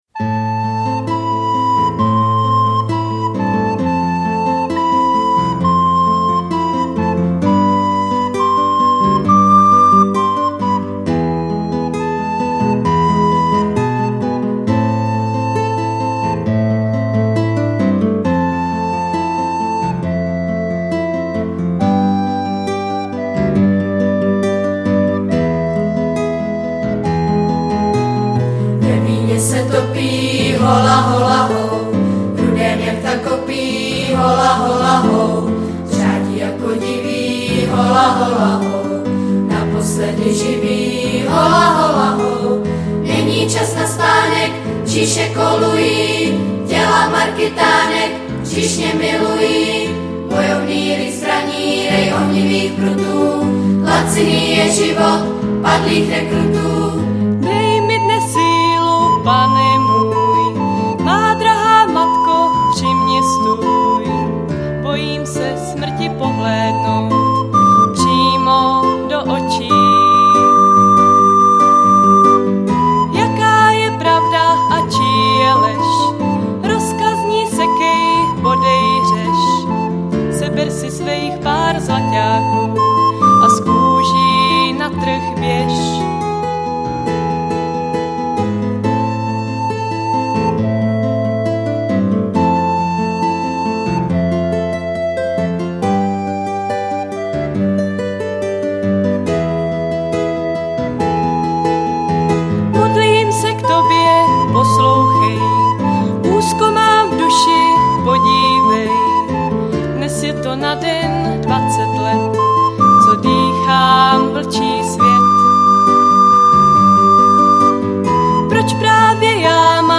Pěvecké sbory